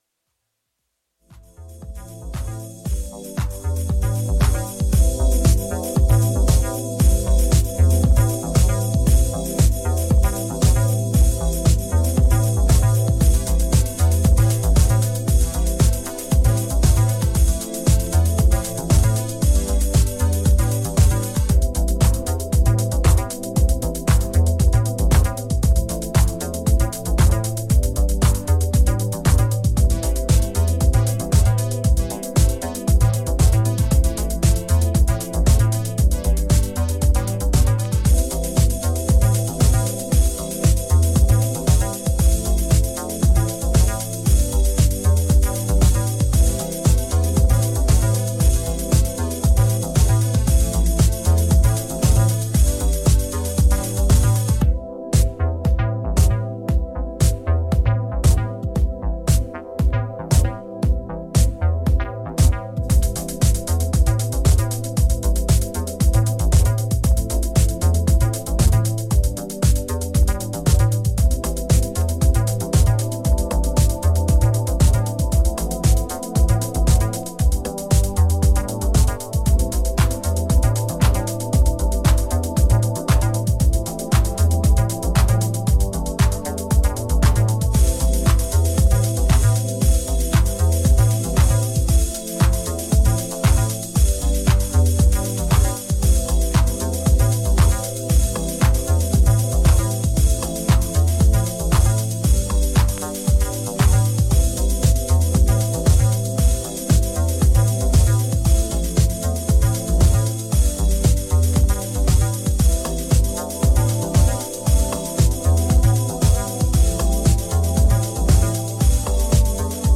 ジャンル(スタイル) CLASSIC HOUSE / DEEP HOUSE / CHICAGO HOUSE